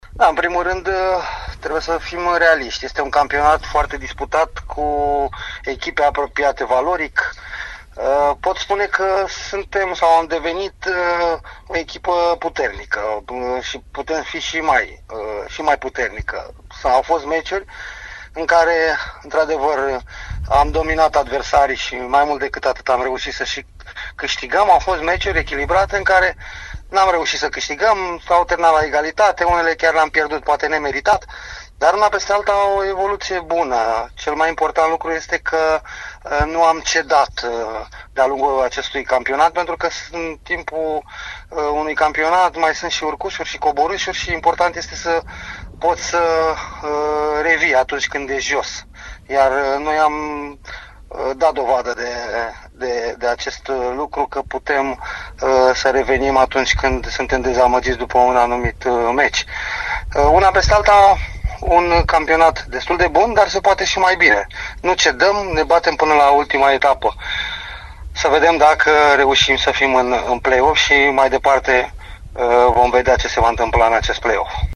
Antrenorul Flavius Stoican a analizat, la Radio Timișoara, parcursul din actuala stagiune a echipei sale.